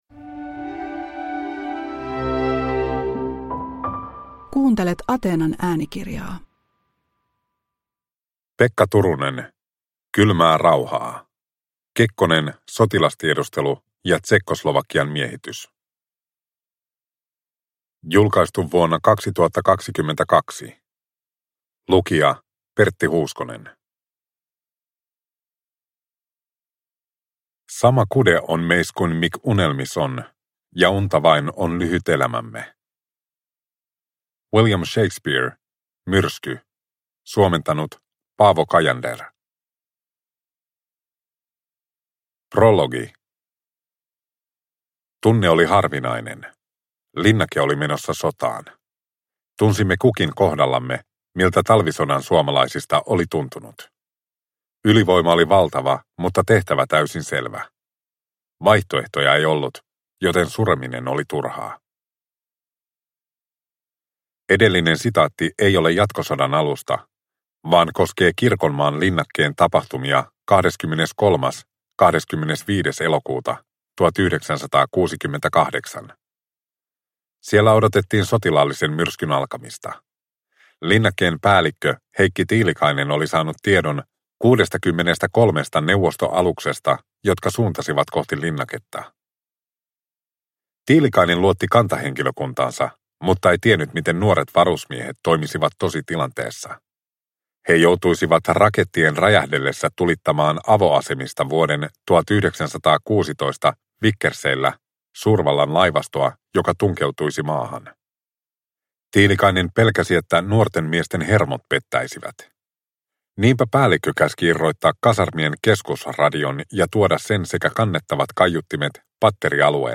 Kylmää rauhaa – Ljudbok – Laddas ner